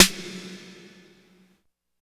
45 SNARE 4.wav